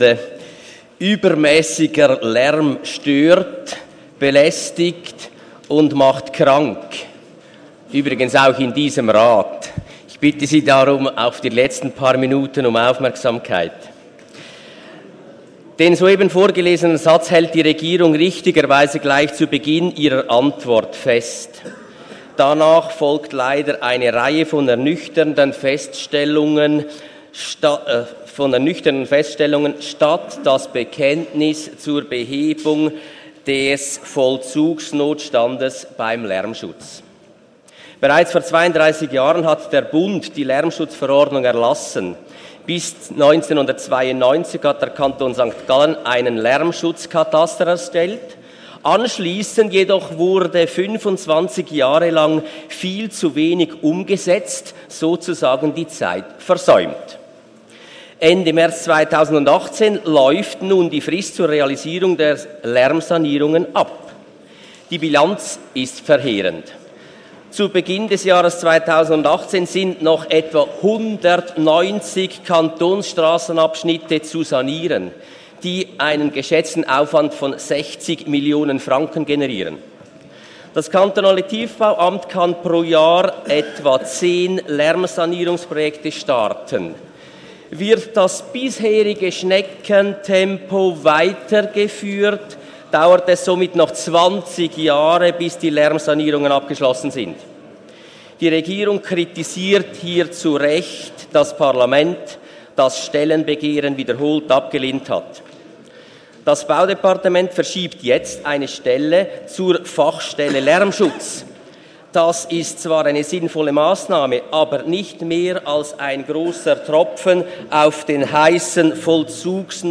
19.2.2018Wortmeldung
Session des Kantonsrates vom 19. und 20. Februar 2018